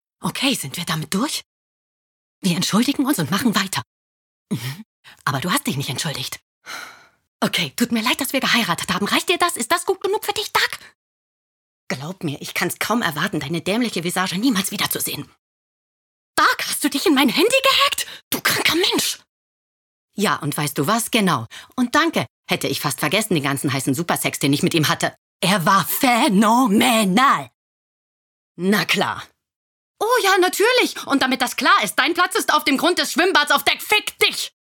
sehr variabel, dunkel, sonor, souverän
Mittel minus (25-45)
Berlinerisch, Norddeutsch, Sächsisch
Synchron Demo (zackig, aufgebracht)
Lip-Sync (Synchron)